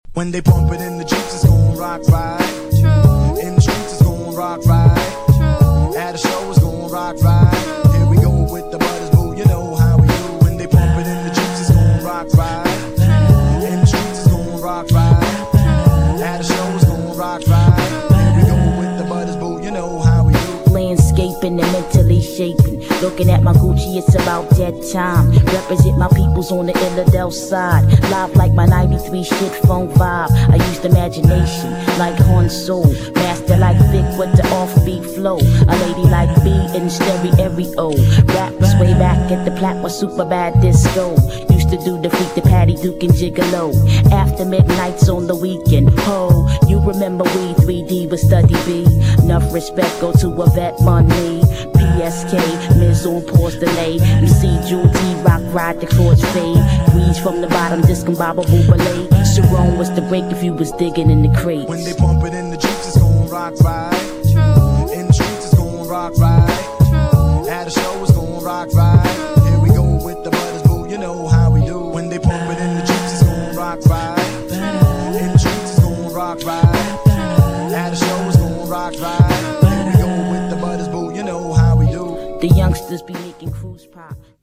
GENRE Hip Hop
BPM 91〜95BPM
# SMOOTHなフロウ
# クロいHIPHOP # フィーメイルRAP # メランコリックなネタ # メロディアスなHIPHOP